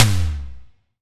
Index of /90_sSampleCDs/EdgeSounds - Drum Mashines VOL-1/SIMMONSDRUMS